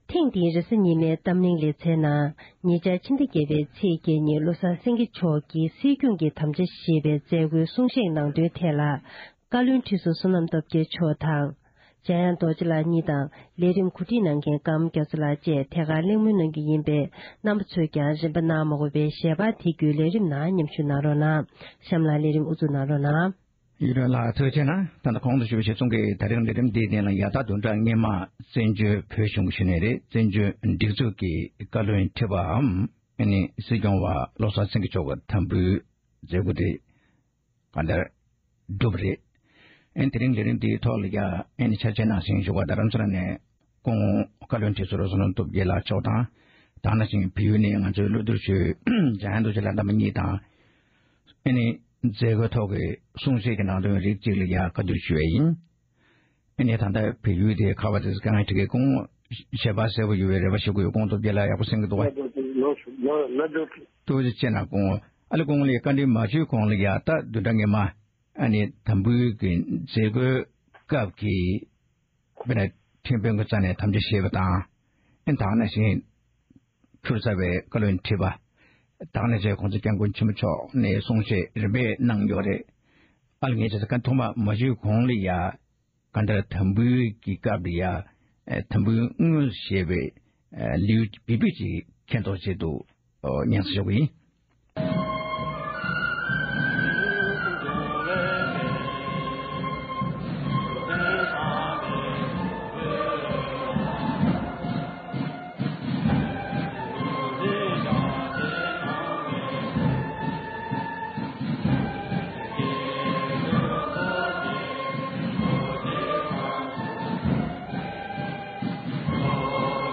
ཐེངས་འདིའི་རེས་གཟའ་ཉི་མའི་གཏམ་གླེང་གི་ལེ་ཚན་ནང་དུ